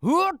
CK蓄力09.wav
人声采集素材/男2刺客型/CK蓄力09.wav